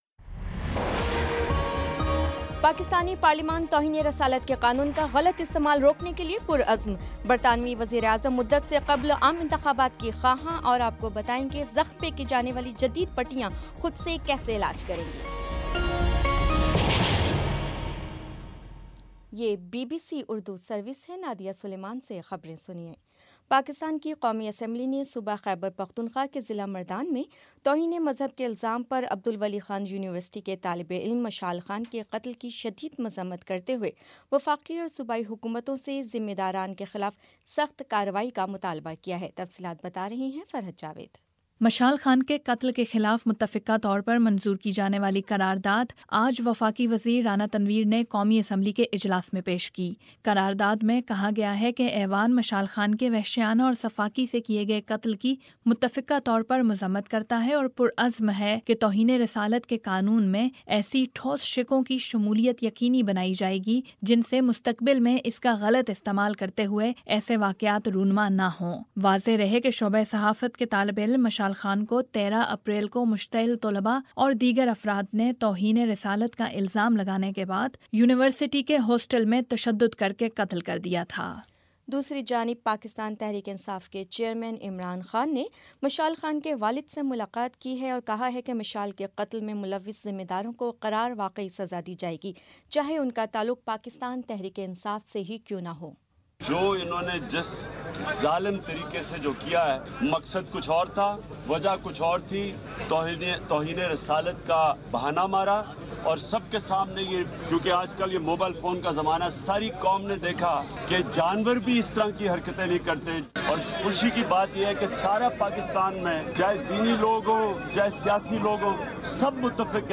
اپریل 18 : شام پانچ بجے کا نیوز بُلیٹن